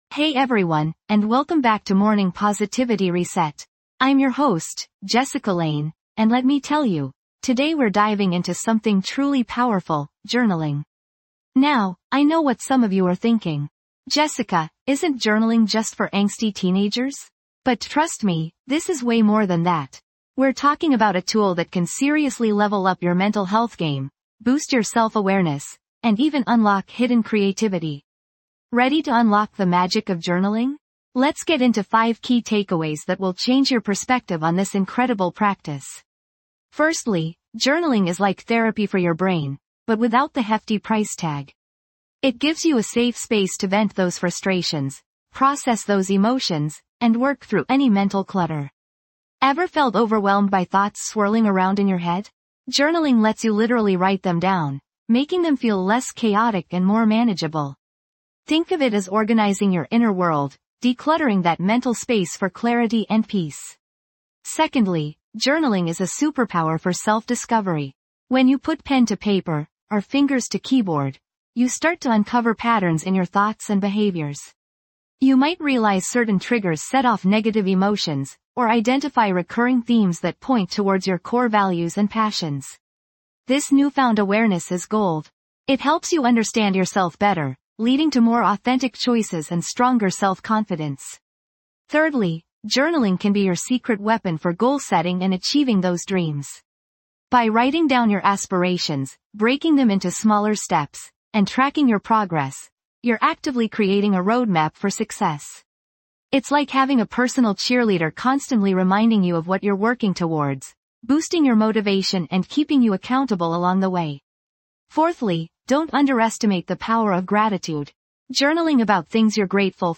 Each episode features soothing guided meditations, calming affirmations, and inspirational prompts designed to cultivate inner peace, clarity, and a sense of well-being. Whether you're feeling stressed, overwhelmed, or simply need a moment of quiet grounding, this podcast provides a gentle reset for your mind, helping you approach each day with renewed energy and a positive outlook.